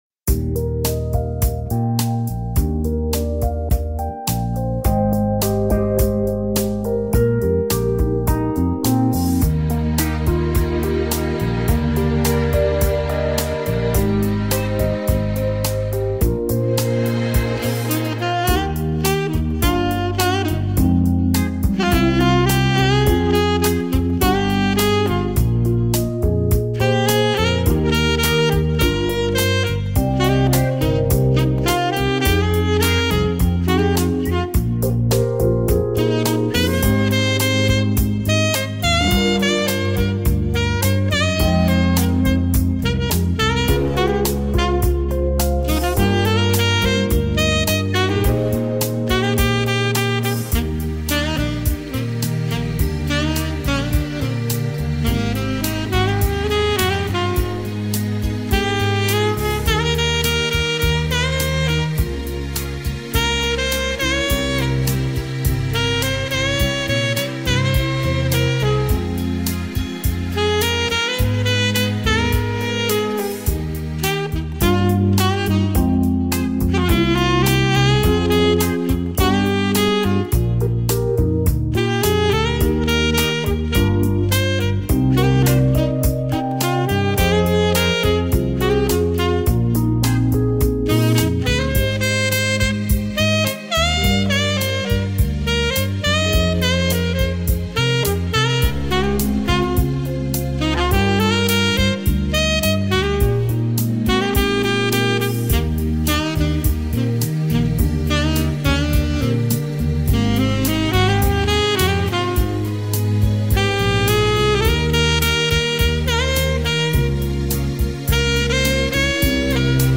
SAX - Latino